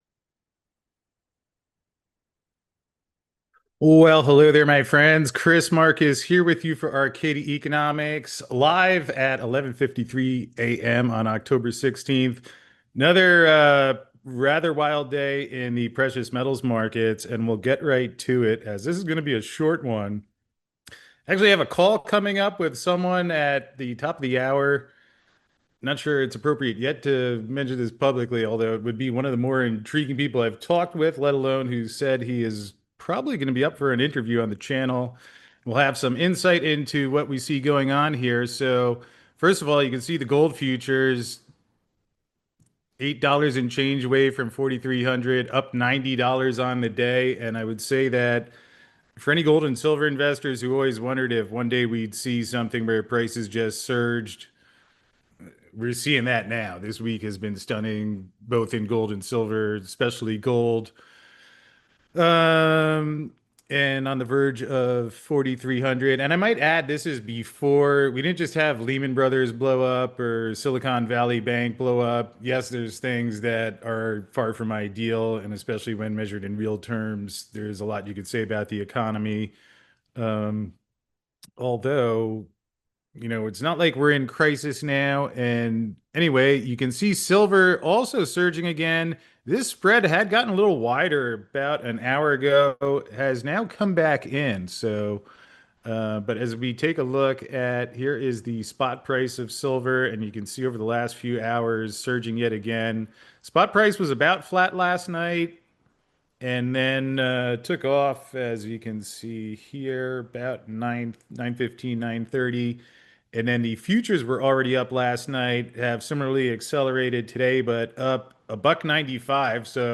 us for this brief live update at 11:50 AM Eastern.